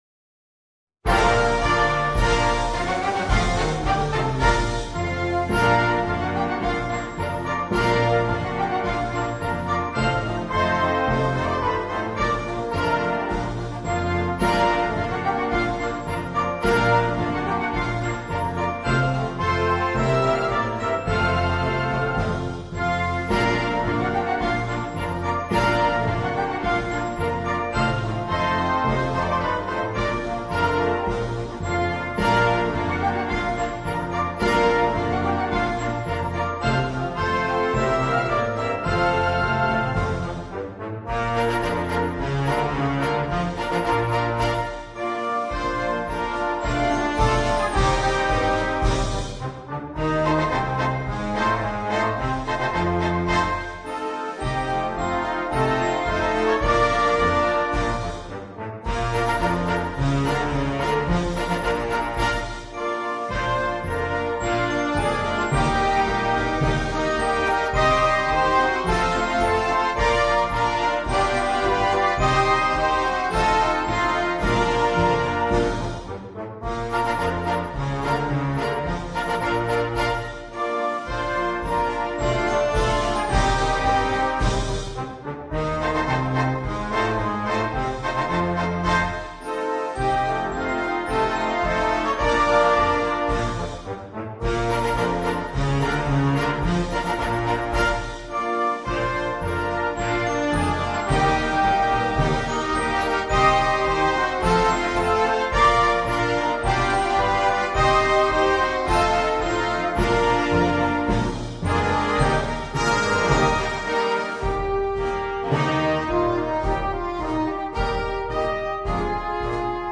MUSICA PER BANDA
Marcia da parata